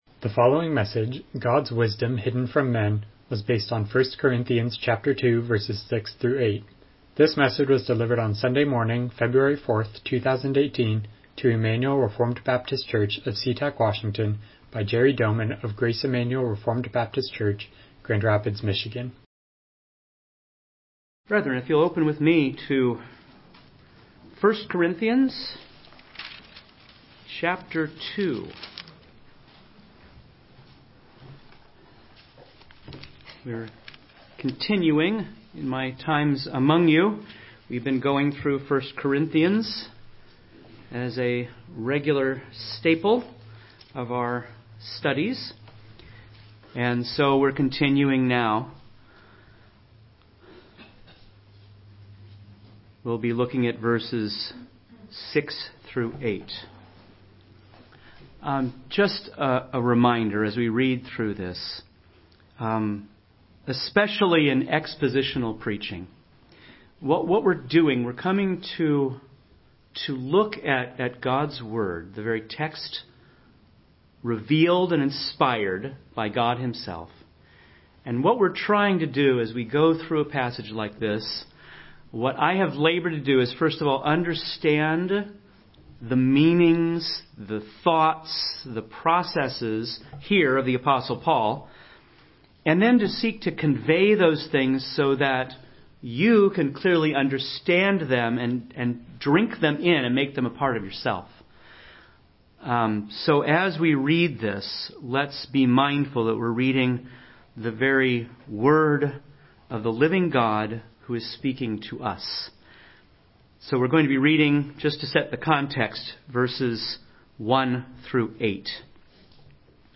Passage: 1 Corinthians 2:6-8 Service Type: Morning Worship